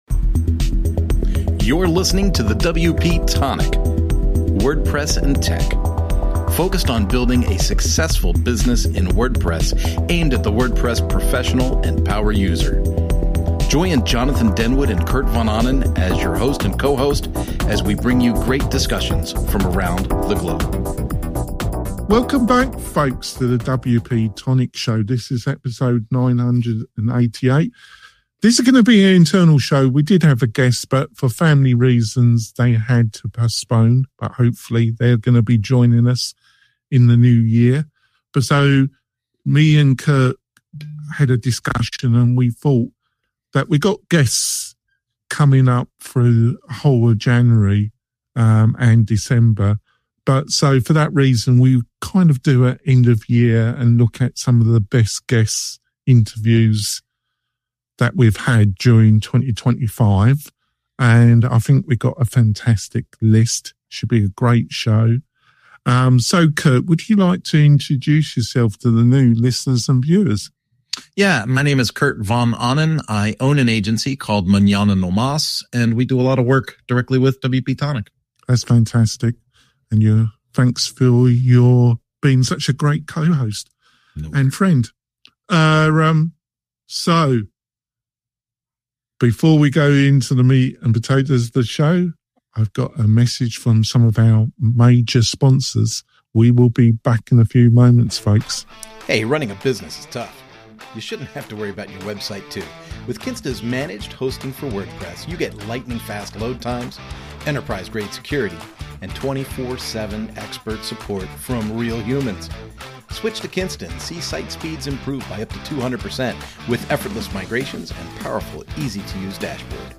We interview creative WordPress and startup entrepreneurs, plus online experts who share insights to help you build your online business.